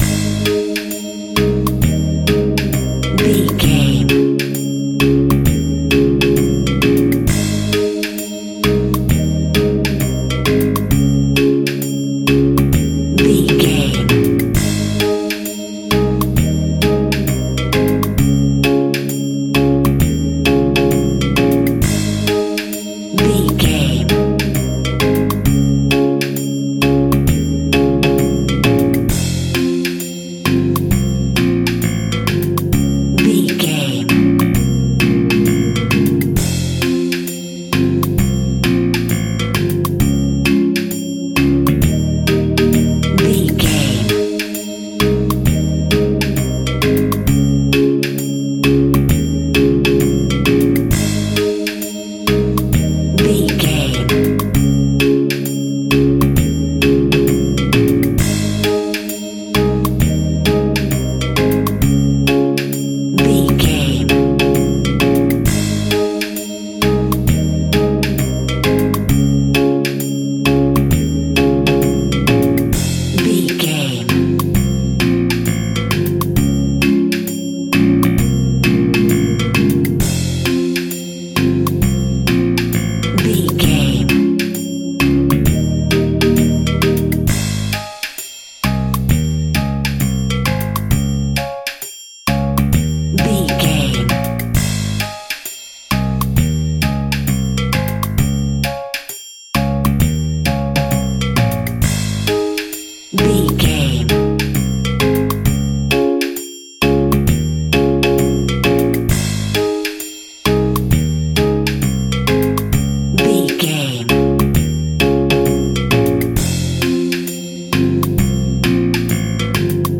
A summer time Reggae Track.
Ionian/Major
A♭
Slow
jamaican
tropical